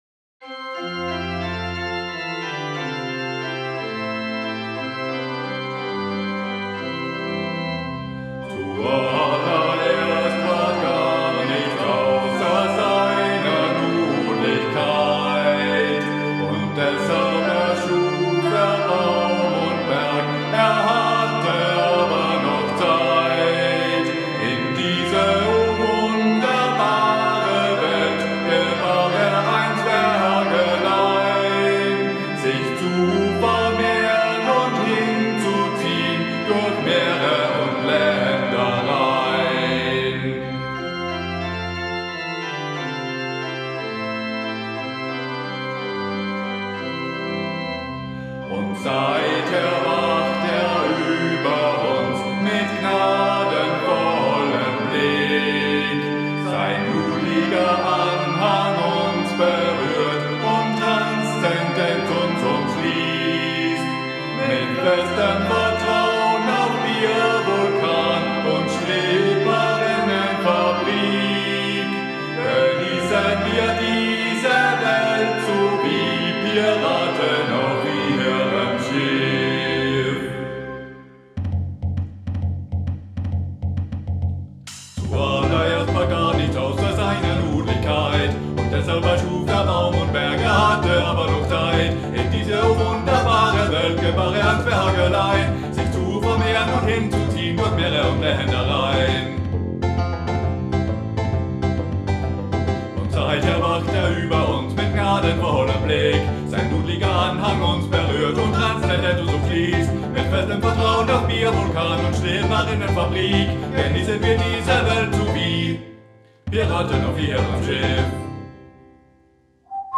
Ein pastafarisches Wein-Achtslied.